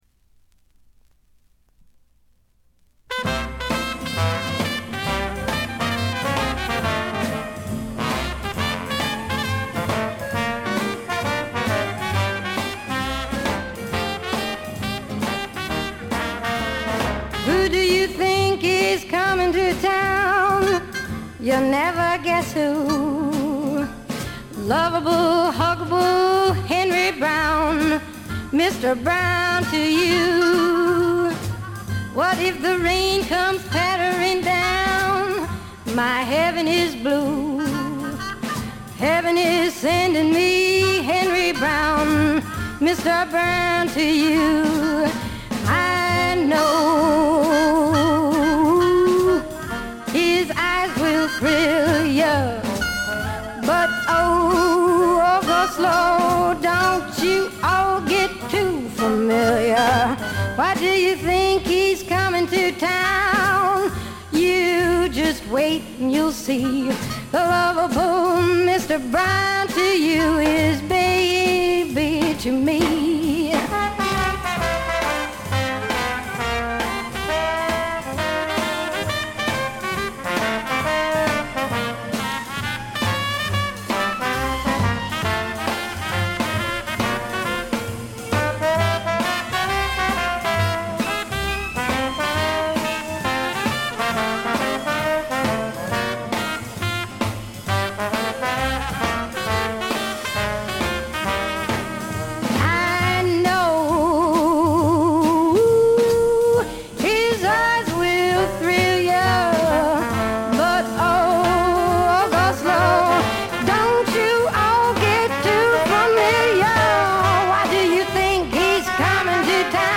微細なノイズ感のみ。
ここでの彼女はみずからギターを弾きながら歌う渋い女性ブルースシンガーという側面を見せてくれます。
激渋アコースティック・ブルースにオールドジャズやR&Bのアレンジが施されたサウンド作りもいい感じですね。
試聴曲は現品からの取り込み音源です。